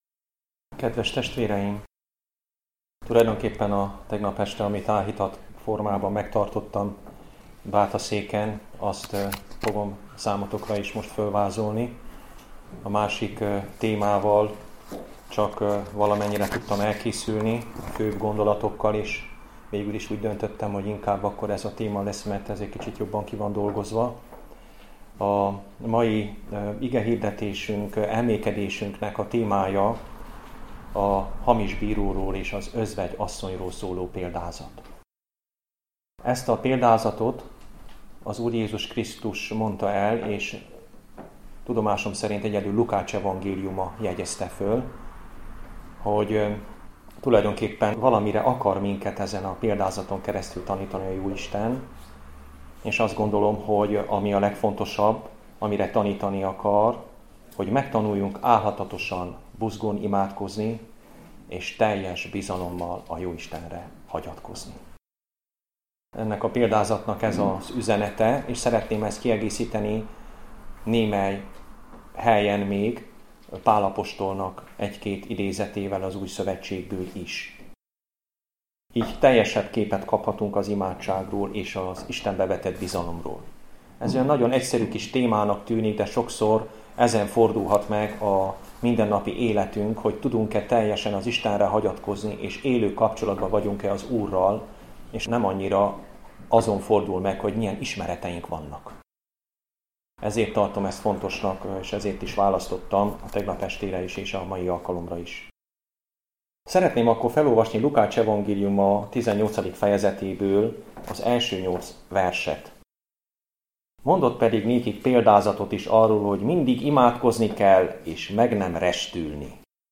Állhatatosan imádkozni és teljes szívvel bízni az Istenben Igehirdetések mp3 Az igehirdetéshez kattints ide Hasonló bejegyzések Igehirdetések mp3 Ti vagytok a föld sója.